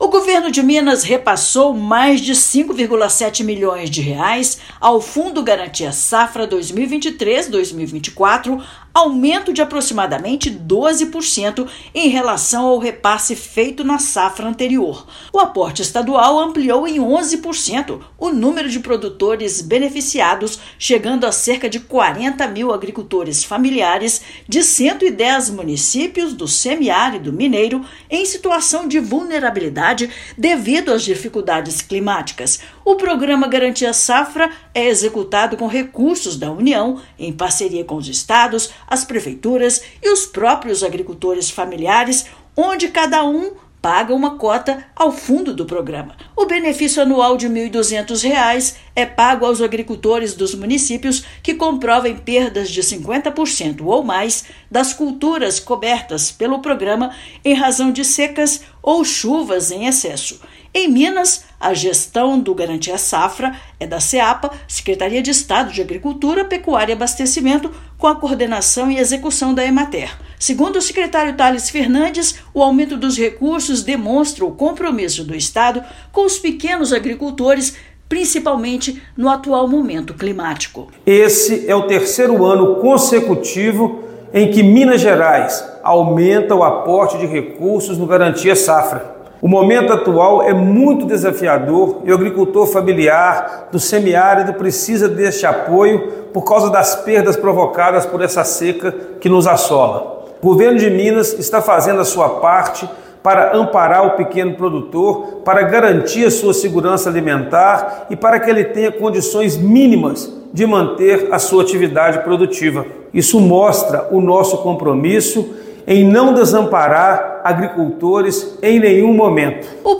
Com aumento de 11,8% no valor, recursos do Garantia-Safra vão beneficiar cerca de 40 mil agricultores familiares em caso de perdas nas lavouras por problemas climáticos. Ouça matéria de rádio.